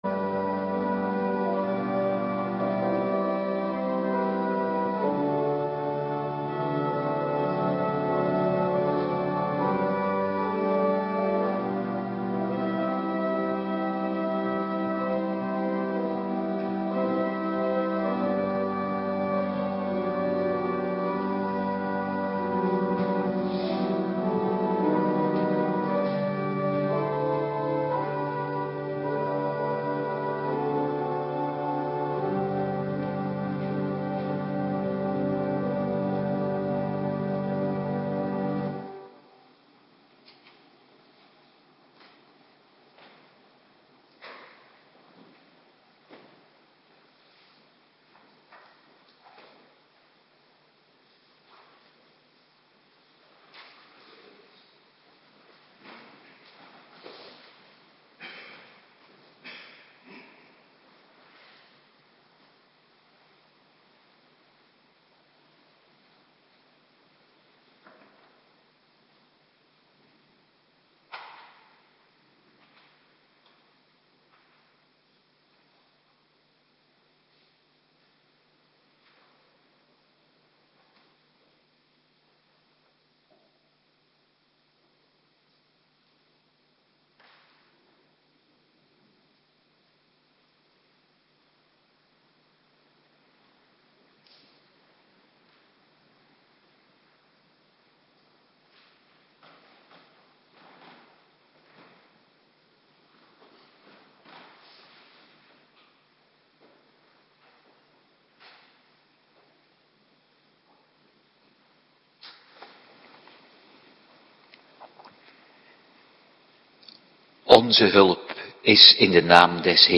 Terug Bekijk in volledig scherm Download PDF Morgendienst
Locatie: Hervormde Gemeente Waarder